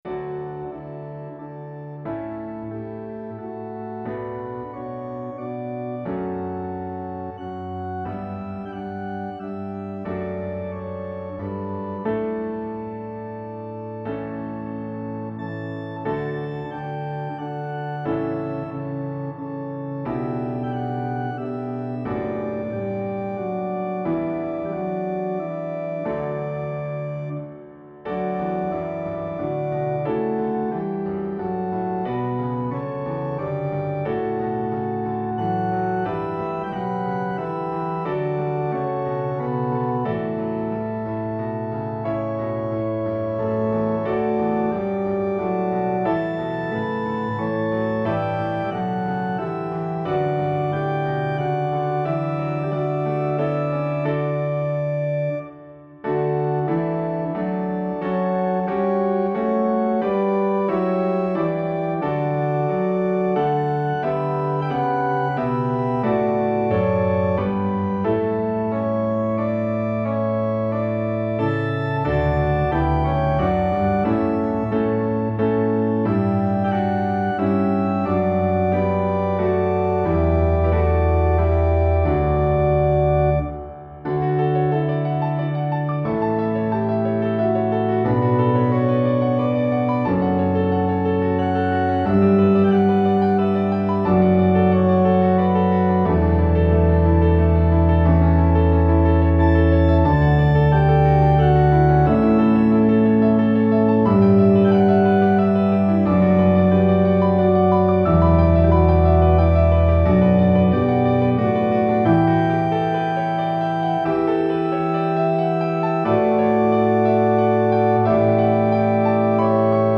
SATB with Piano.
(Vocal parts played on Organ)